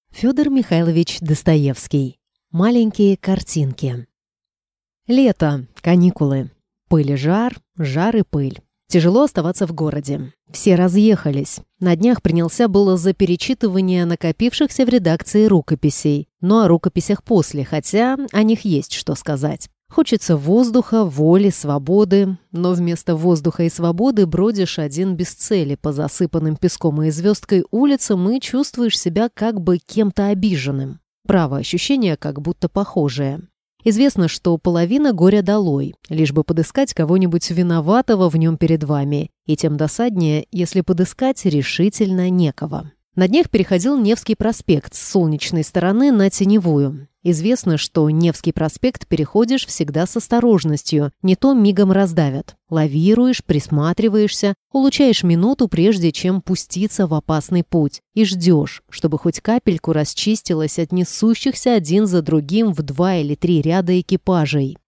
Аудиокнига Маленькие картинки | Библиотека аудиокниг